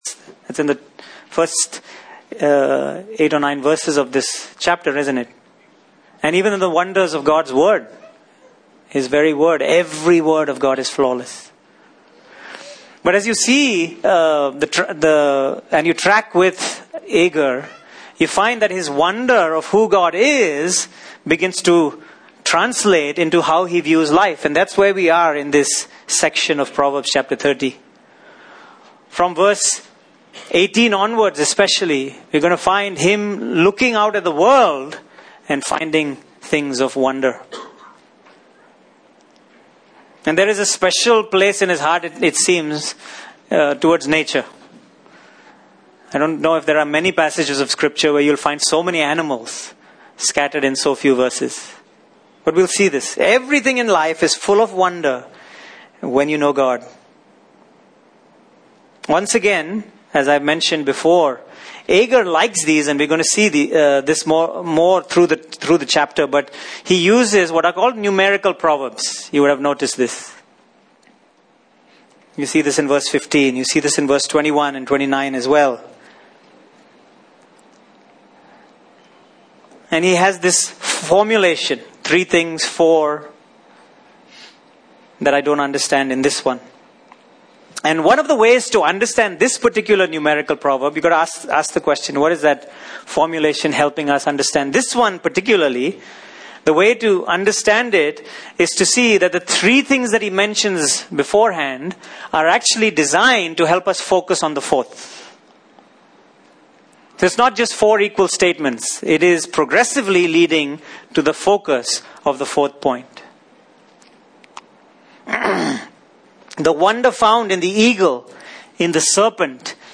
Passage: Proverbs 30:18-20 Service Type: Sunday Morning